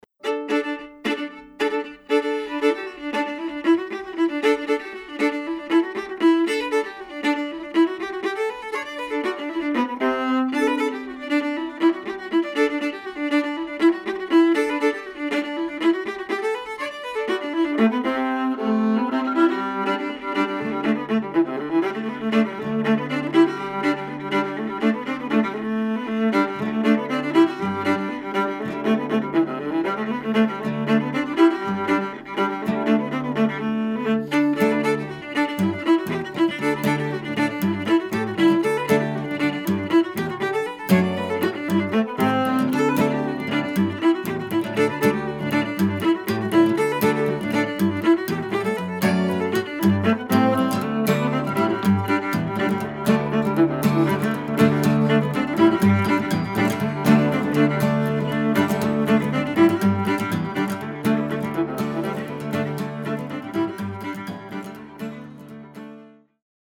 Original New England-style dance tunes
guitar, bouzouki
fiddle, viola
cittern, cross-tuned guitar